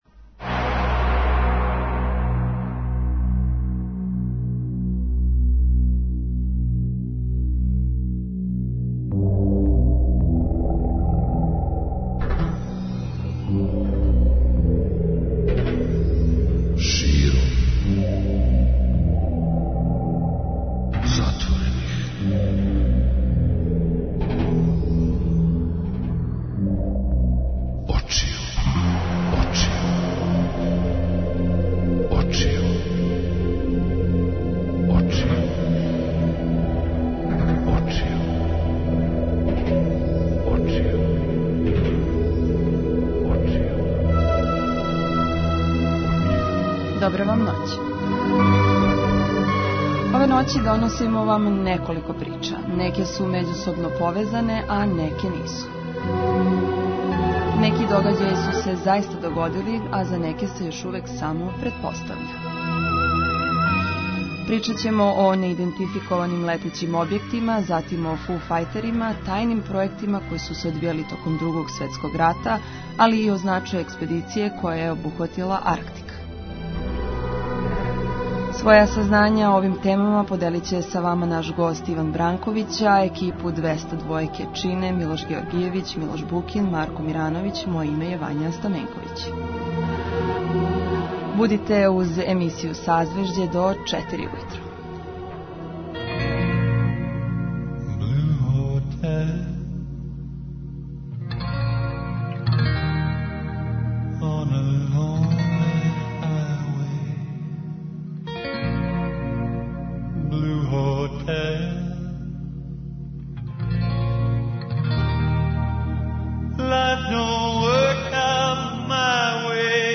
Гост и саговорник